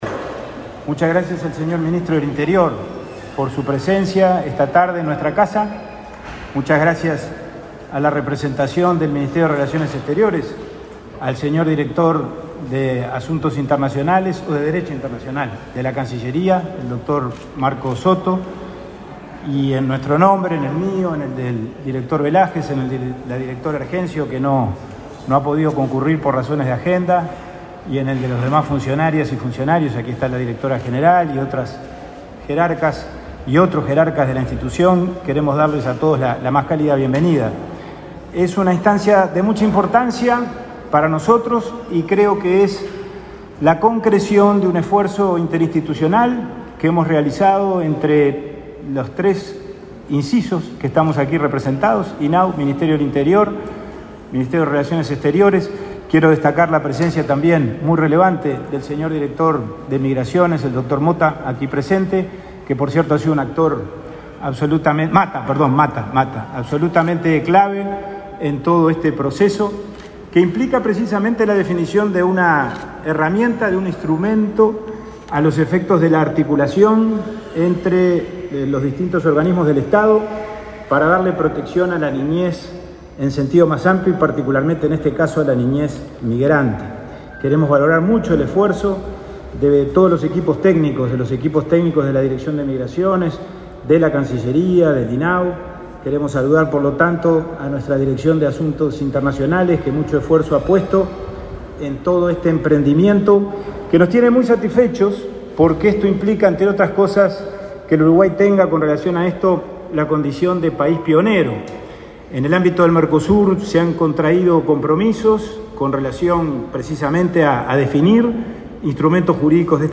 Conferencia de prensa por la firma de convenio referido a niños inmigrantes
Tras el acto, el ministro de Interior, Luis Alberto Heber, y el presidente de INAU, Pablo Abdala, realizaron de prensa.